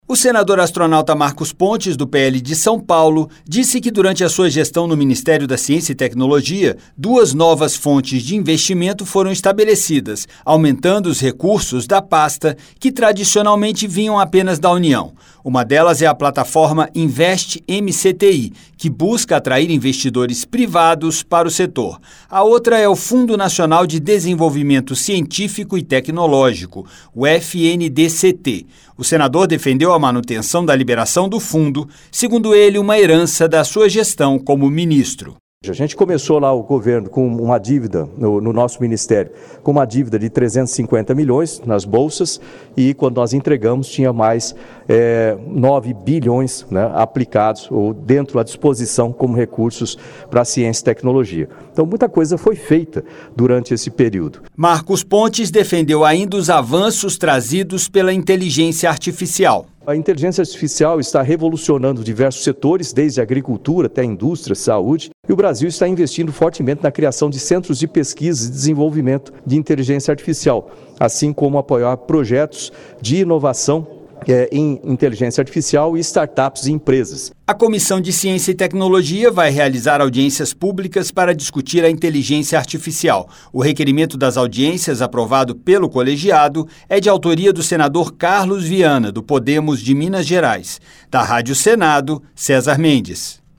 Plenário do Senado
Pronunciamento